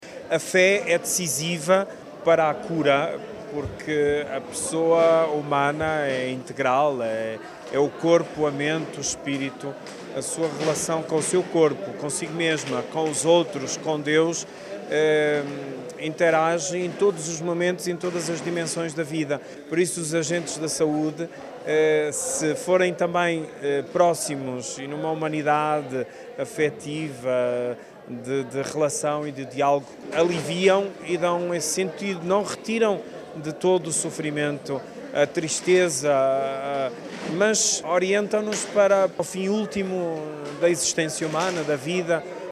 Uma associação que é essencial para o processo de cura, considera D. José Cordeiro, Bispo da diocese Bragança-Miranda.
Declarações à margem da assinatura do protocolo de colaboração entre a ULS Nordeste e a UPSA, inserida no Seminário “Saúde – Do cuidar ao curar: a Fé como acto curativo” que aconteceu na manhã de sábado na aldeia de Arcas, Macedo de Cavaleiros.